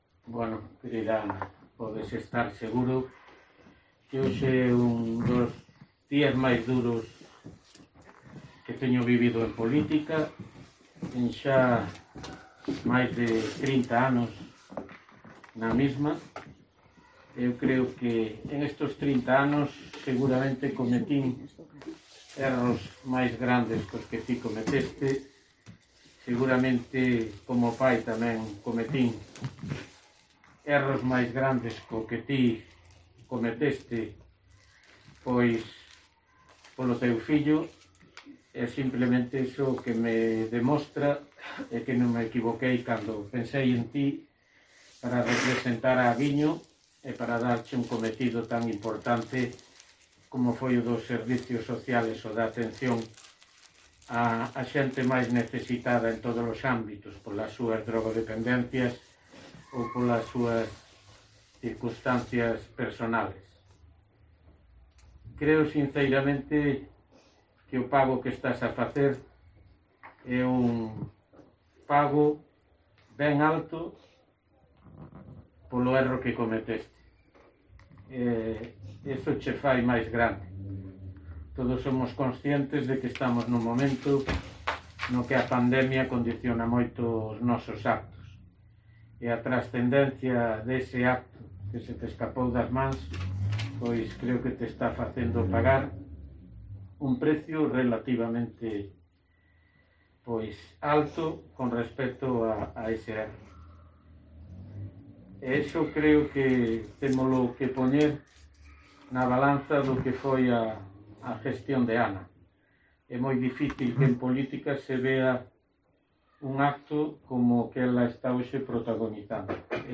Escucha la intervención completa del alcalde de Ribeira, Manuel Ruiz Rivas, tras la dimisión de su concejala